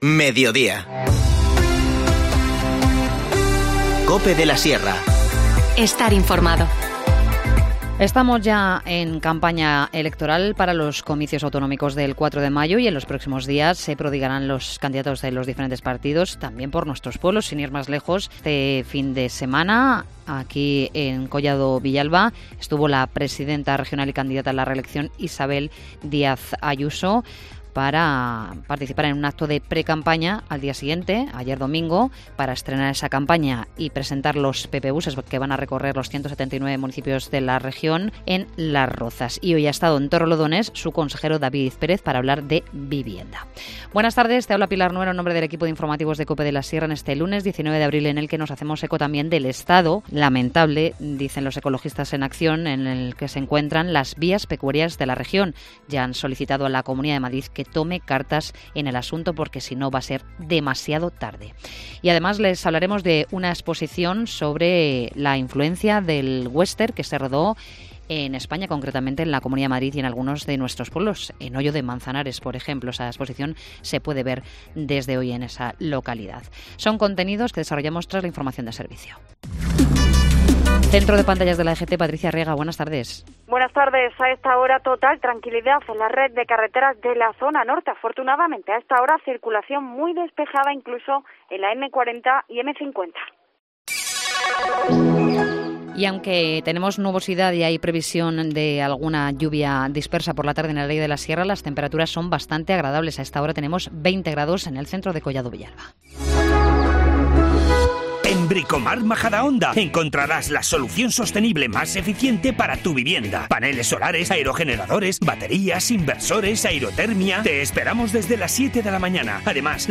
Informativo Mediodía 19 abril